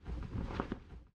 catch_air_7.ogg